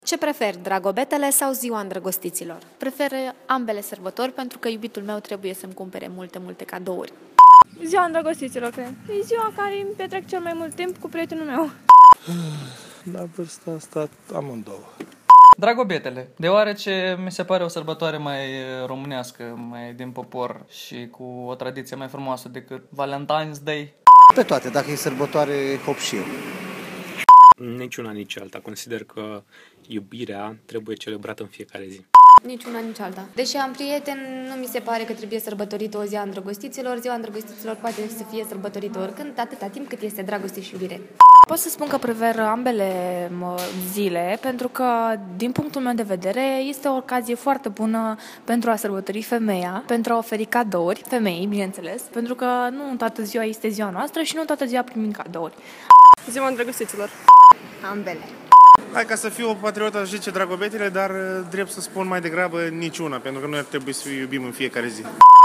Am întrebat câțiva oameni ce preferă: Ziua Îndrăgostiților sau Dragobetele?